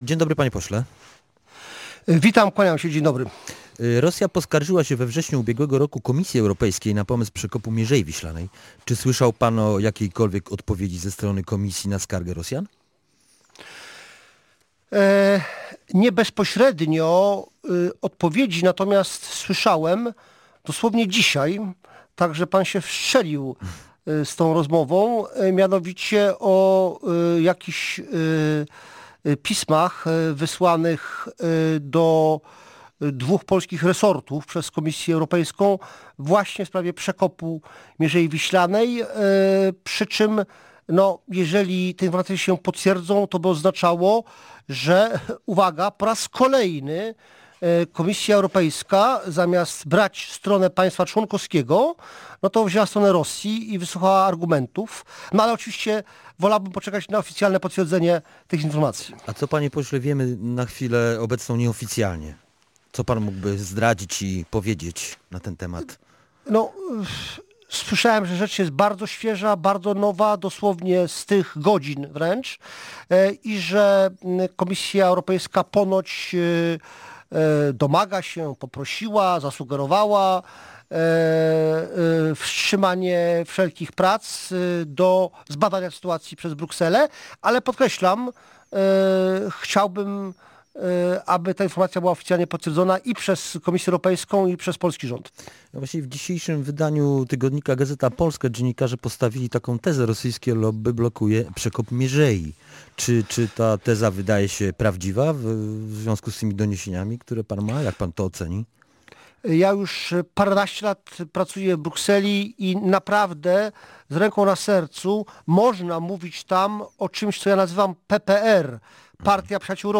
– Jeśli te informacje się potwierdzą, to by oznaczało, że po raz kolejny Komisja Europejska – zamiast brać stronę państwa członkowskiego – wzięła stronę Rosji – mówił poseł Prawa i Sprawiedliwości na antenie Radia Gdańsk komentując możliwe informacje o blokadzie przekopu Mierzei Wiślanej.
Ryszard Czarnecki był Gościem Dnia Radia Gdańsk.